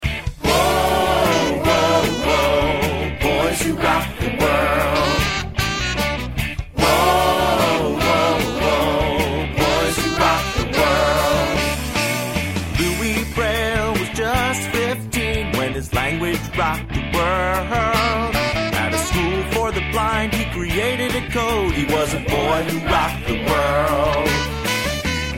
Demo MP3 Listen to a sample of this instrumental song.